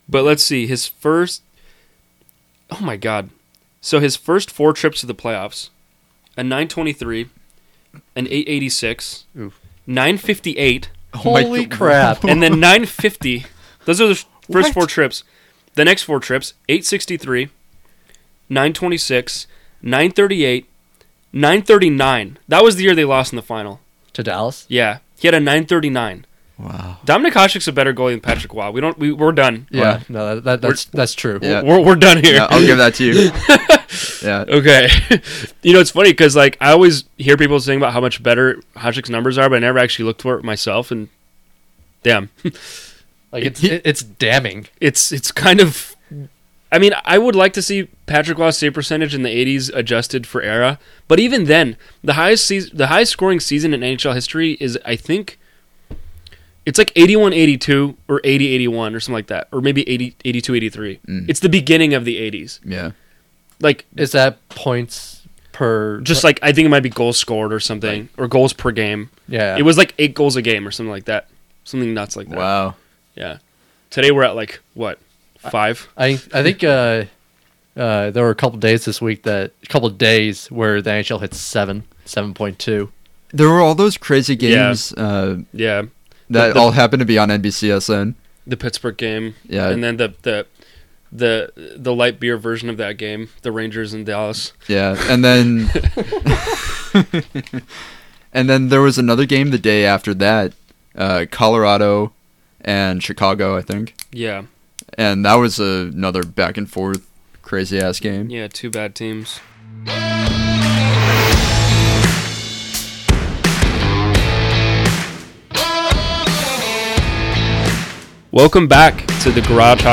are back in the studio for their first episode of 2017! With the mid-way point of the season upon us, the lads discuss who the true contenders are, which teams are on the brink of contention, and which teams have struggled this season. Also, the guys weigh in on the Colorado Avalanche and the trade speculation swirling around their best players.